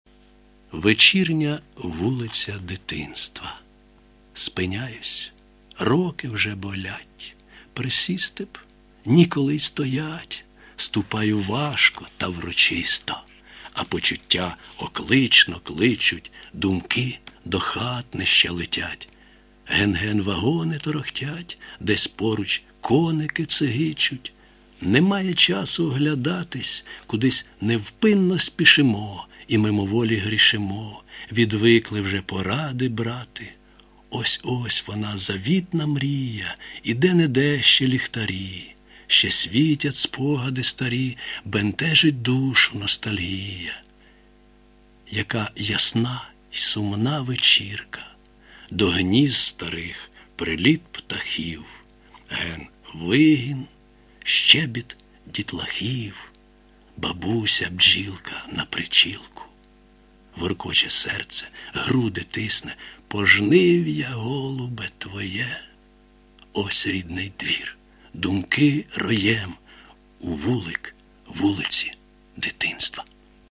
Слухав щойно себе й помітив дві при читанні помилочки - "оклично" замість "дитинно" і "щебіт" замість "щебет".